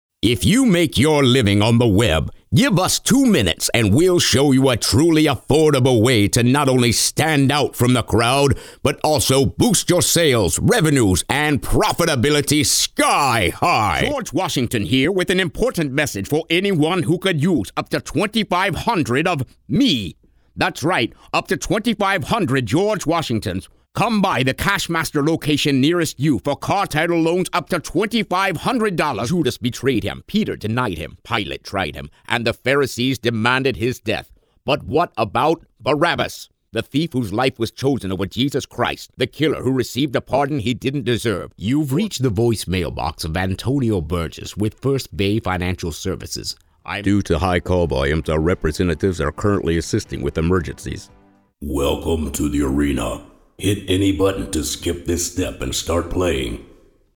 Male
Radio Commercials
Deep Bold Commercial Voices